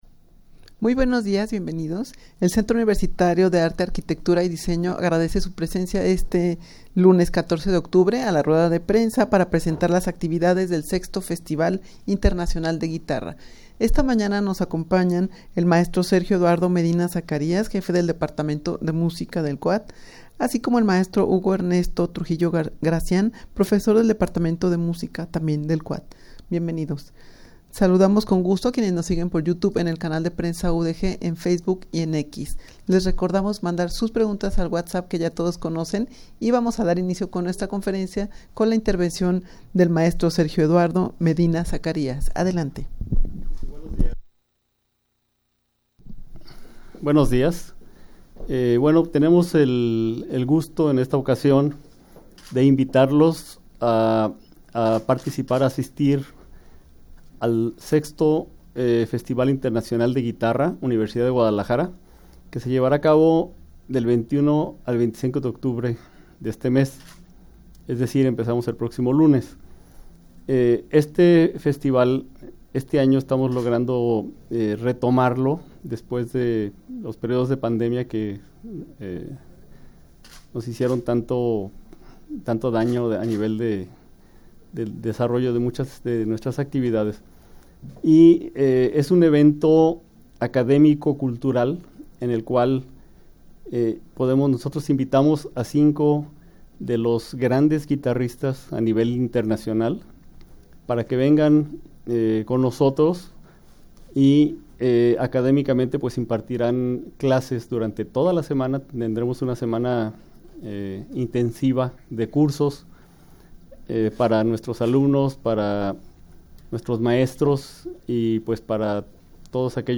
Audio de la Rueda de Prensa
rueda-de-prensa-para-presentar-las-actividades-del-6to-festival-internacional-de-guitarra.mp3